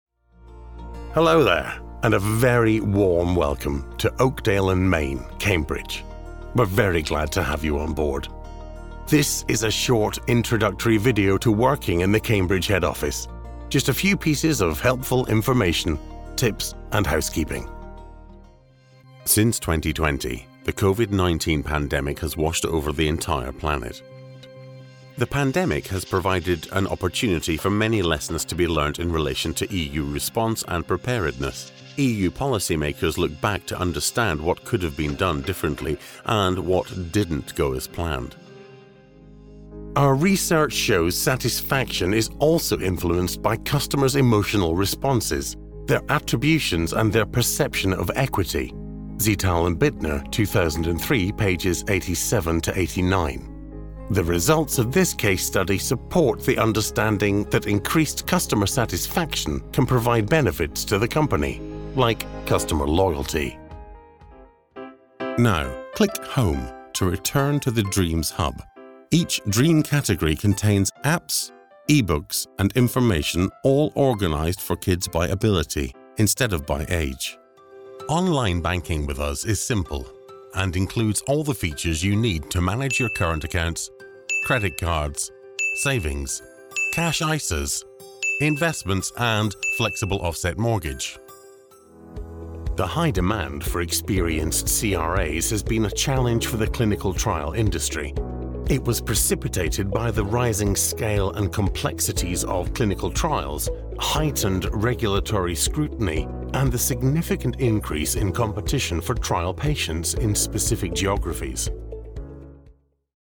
Corporate